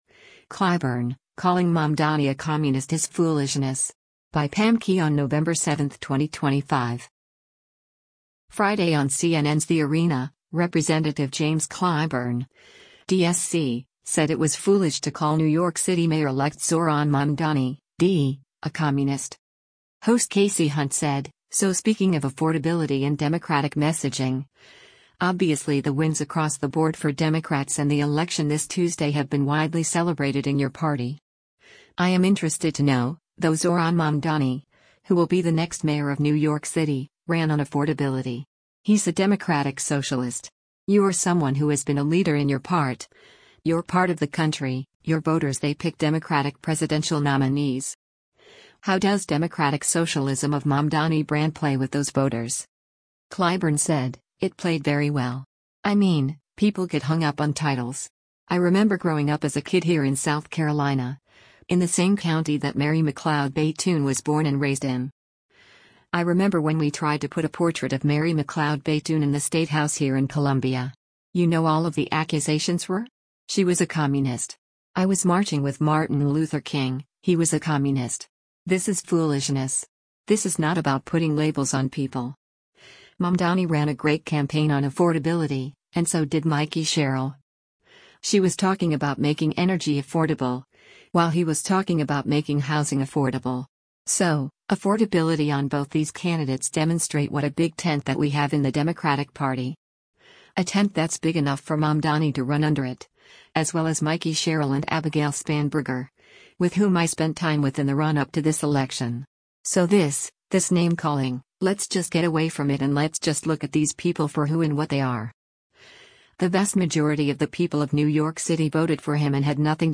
Friday on CNN’s “The Arena,” Rep. James Clyburn (D-SC) said it was foolish to call New York City Mayor-elect Zohran Mamdani (D) a communist.